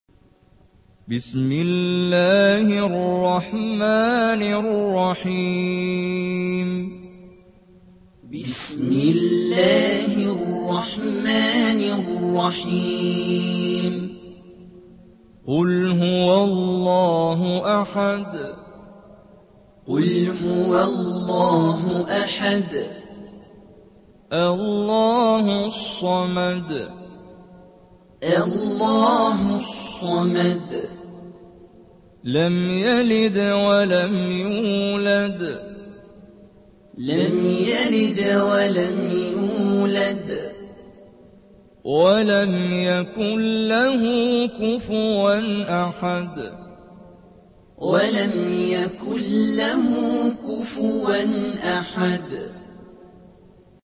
Quran recitations Interpretations Tajweed Quran translations